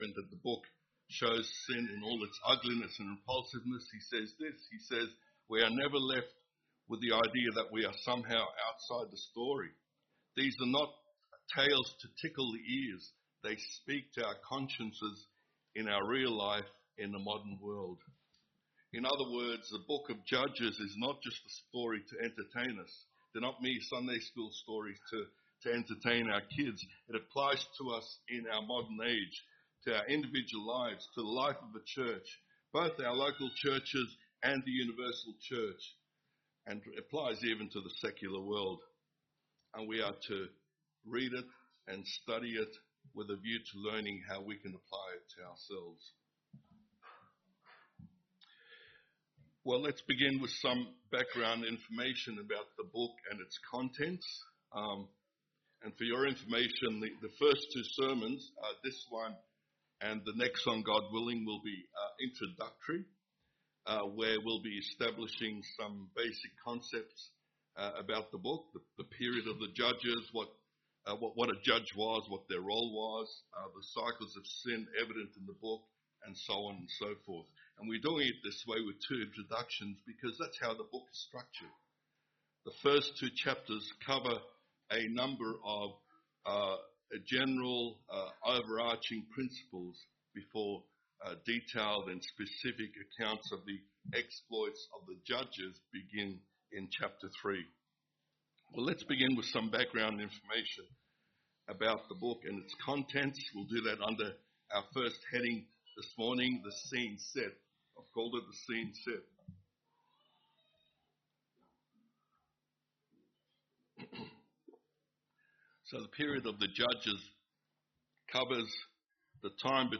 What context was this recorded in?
Note: The recording begins a few minutes into the sermon.